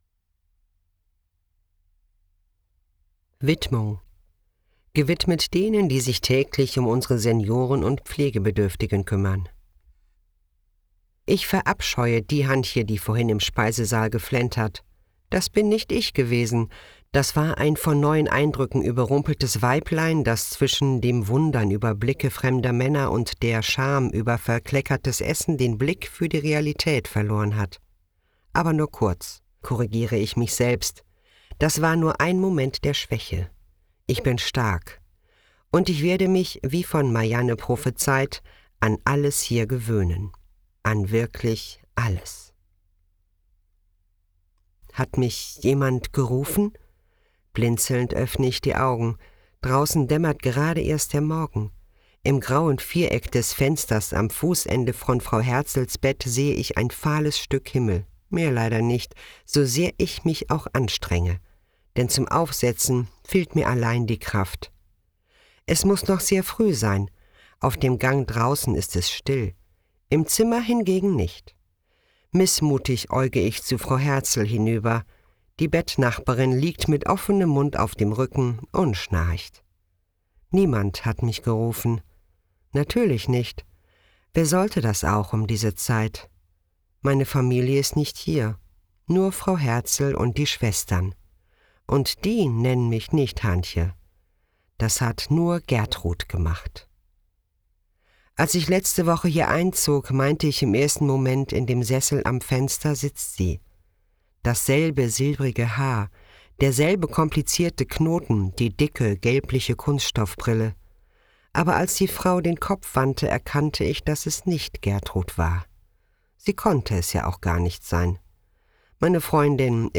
Hörbuch | Familien & Drama